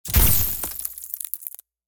ice_blast_04.wav